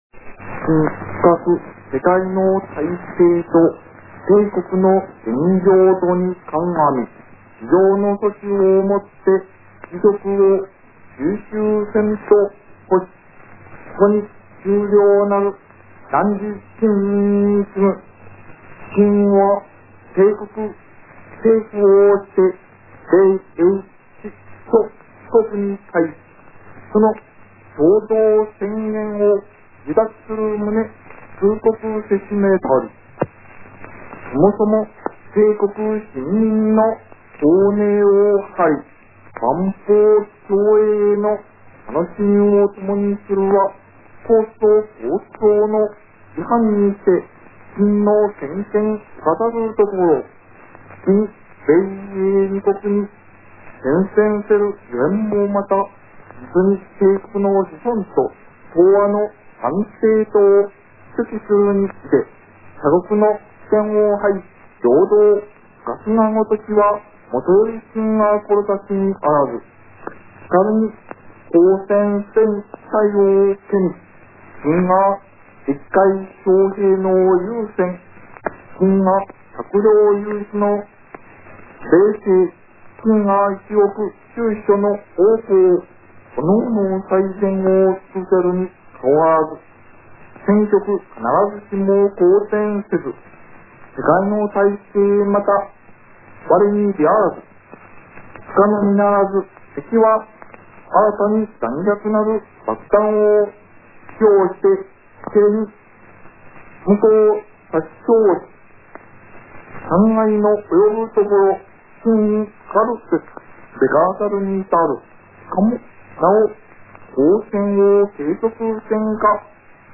玉音放送全音声（ＭＰ３；２７４ＫＢ：４分４１秒）を公開しておきます．皆さん聞いたことありますか？
と思う人が多いんじゃないでしょうかねぇ．当時も「えっこんな声なん？」と思った人も多かったそうです．また，録音状態が悪くかつ漢文調だったので内容が理解できない人も多かったとか．確かにこんな音声を，当時のそれほど性能の良くないラジオで聞いたら何言ってるかわからんわな……．